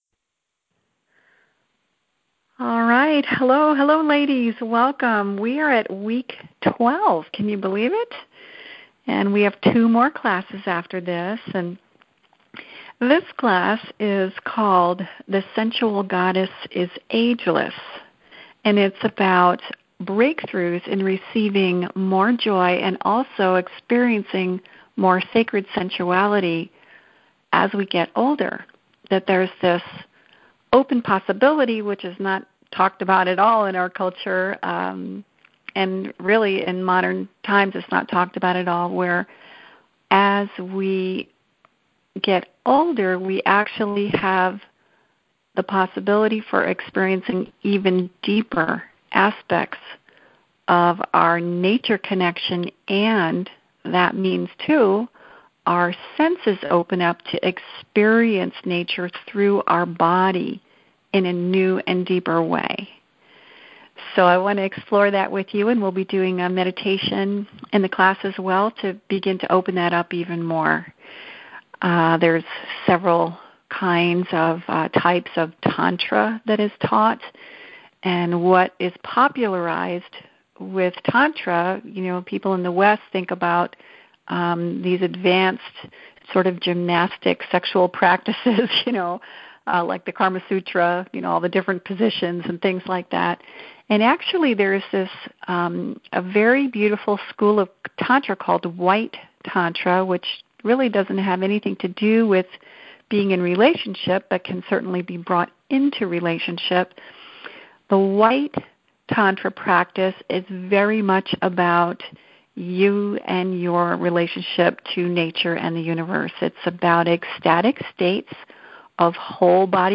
Click the play button below to replay our class or "right-click" and click "Save As" on the link underneath to download the file for your library.